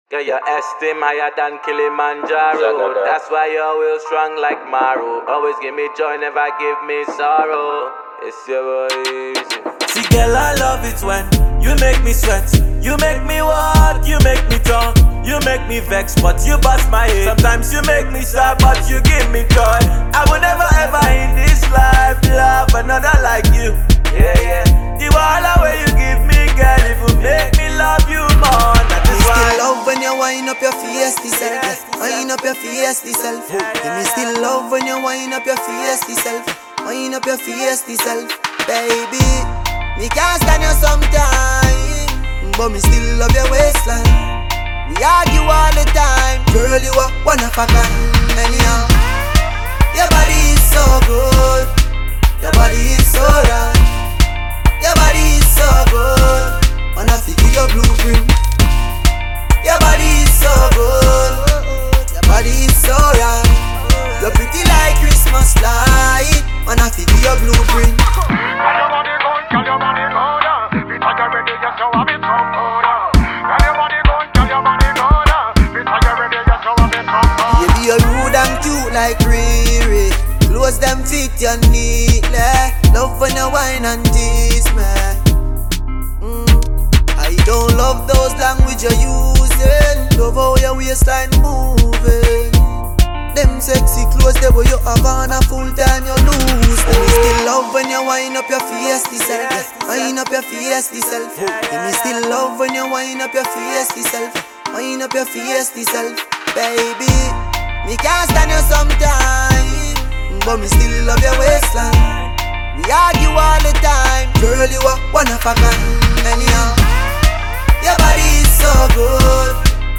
Jamaican Music
Afrobeats